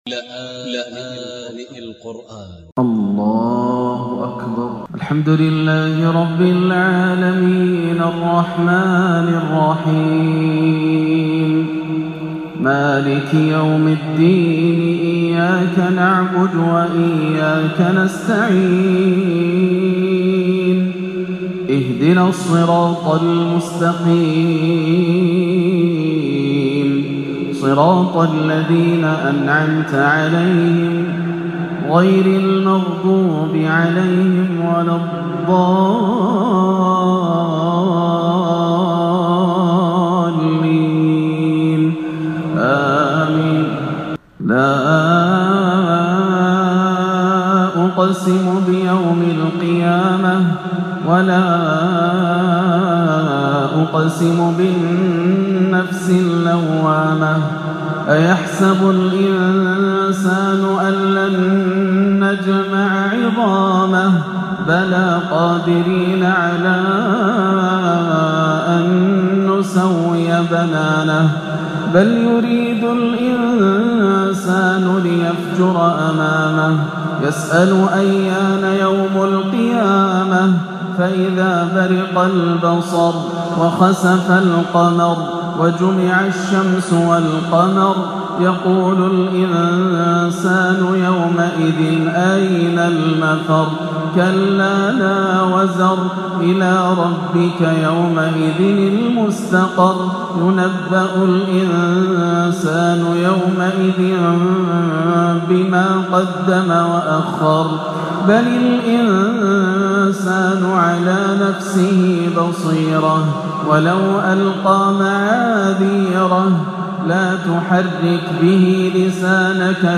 تلاوة خاشعة على مقامي الصبا والكرد لسورتي القيامة و الانفطار عشاء 8-3-1439 > عام 1439 > الفروض - تلاوات ياسر الدوسري